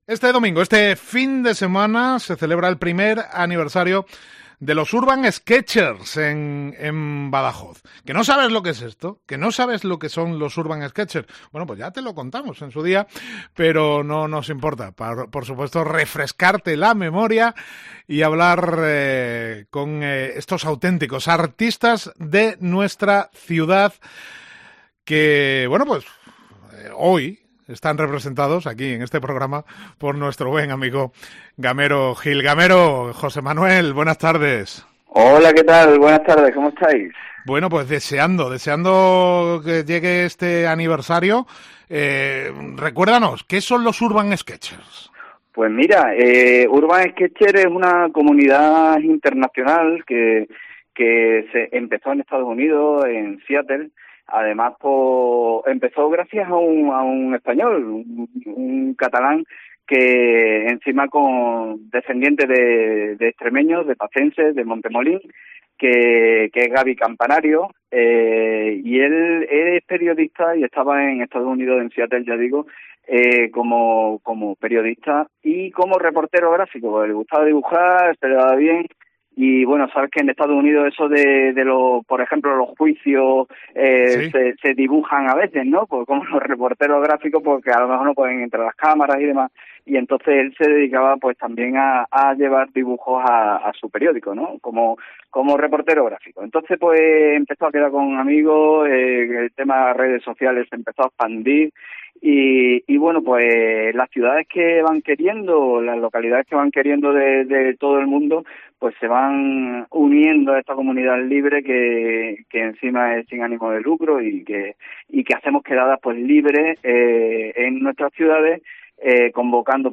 En Directo COPE BADAJOZ